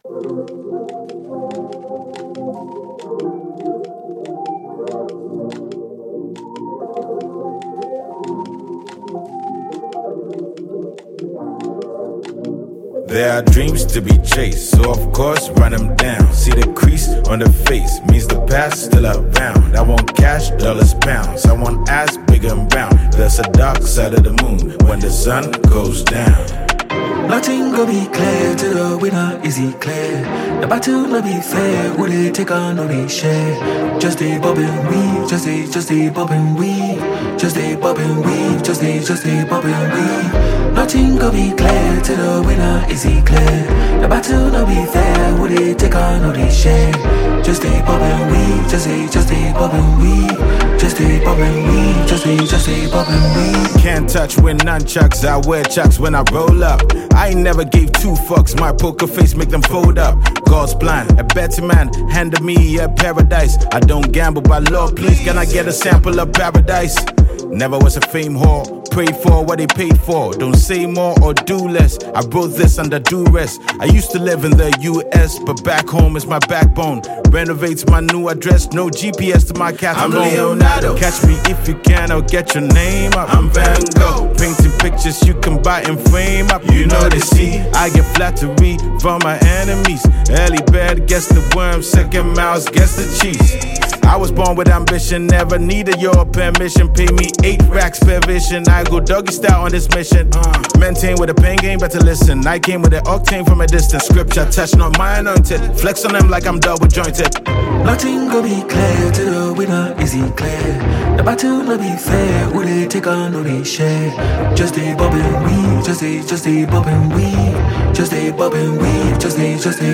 Ghanaian versatile rapper
featuring Ghanaian rapper